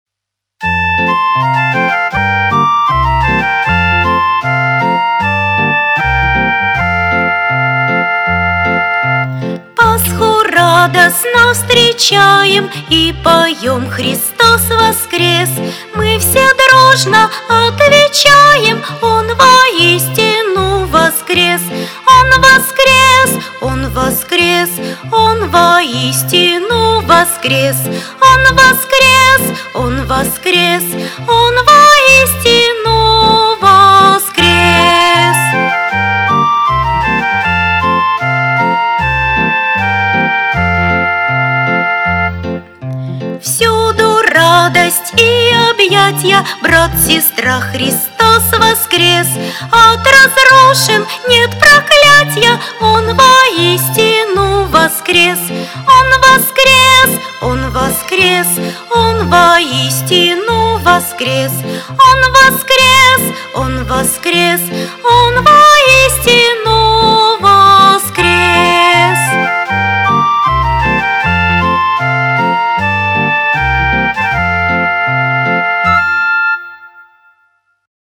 детской песни
фонограмму (плюс)